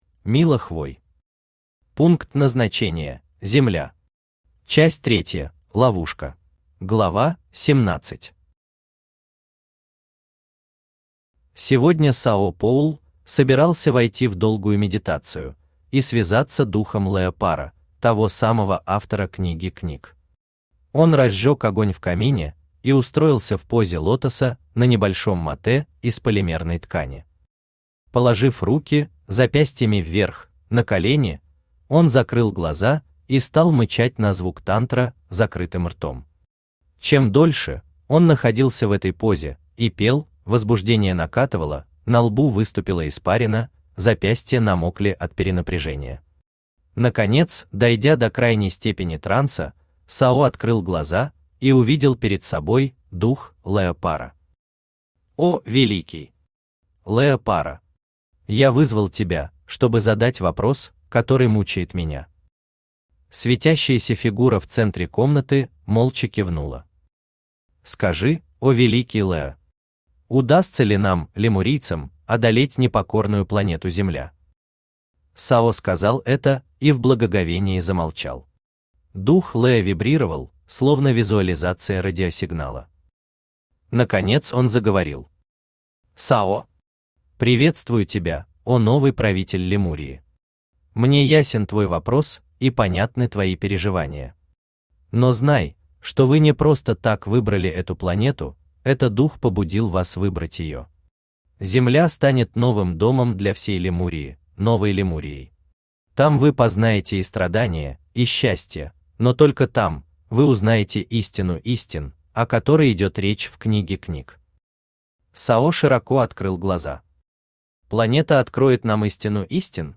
Часть третья: Ловушка Автор Мила Хвой Читает аудиокнигу Авточтец ЛитРес.